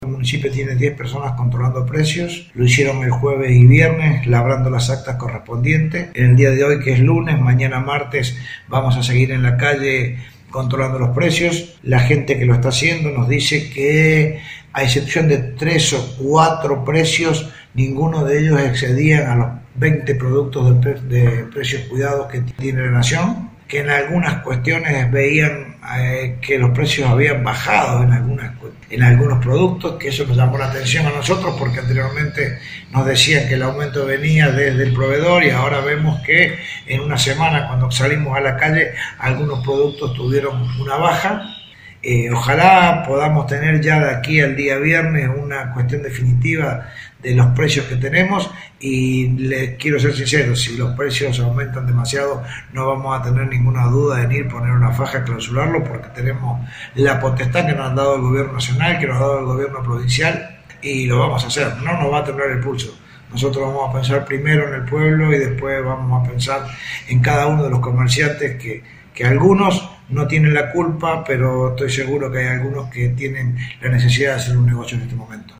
Al respecto, el intendente Fabián Francioni dijo que, salvo 3 o 4, los precios están en sintonía con los de referencia.